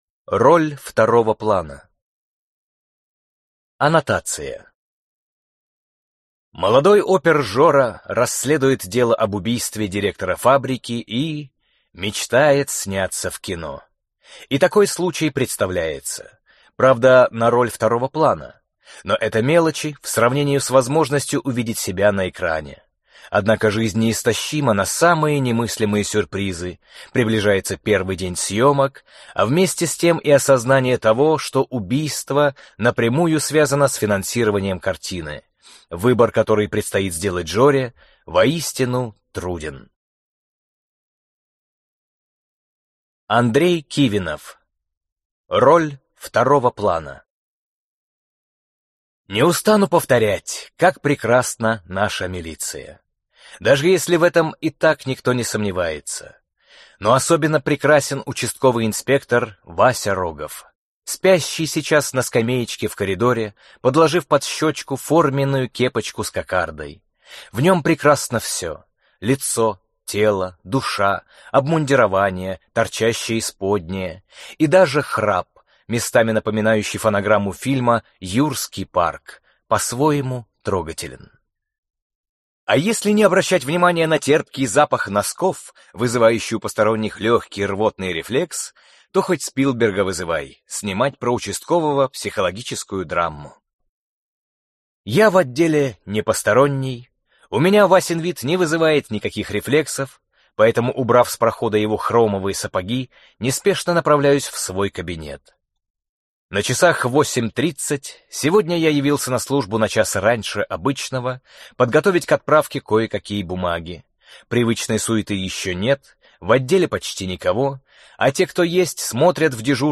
Аудиокнига Роль второго плана | Библиотека аудиокниг